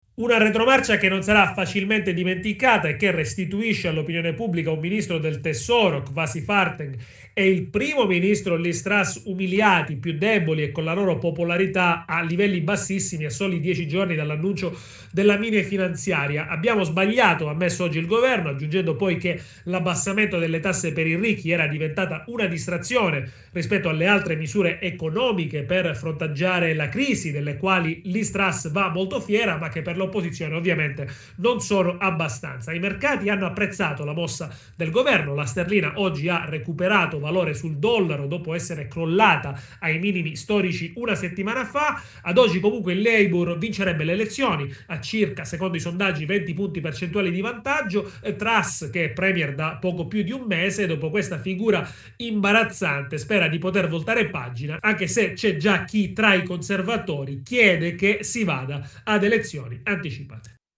Dalla Gran Bretagna